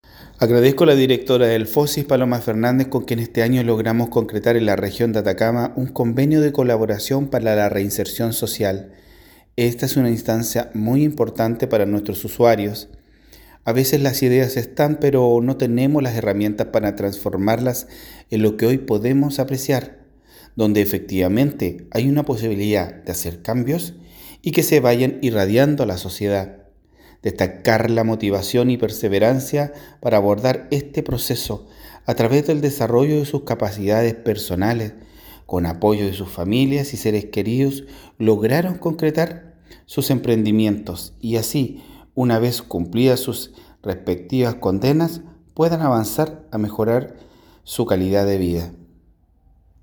En la Plaza Ambrosio O’Higgins de Vallenar se realizó la ceremonia de cierre del programa Emprendo Semilla, iniciativa impulsada por Fosis en colaboración con Gendarmería de Chile.
El Director Regional de Gendarmería, Coronel Jorge Toledo Solorza, destacó la relevancia del convenio firmado con Fosis, que permite ofrecer a los usuarios en proceso de reinserción el apoyo necesario para transformar sus ideas en negocios viables.
Jorge-Toledo-Director-Gendarmeria-Atacama.mp3